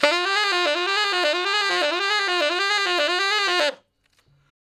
066 Ten Sax Straight (D) 41.wav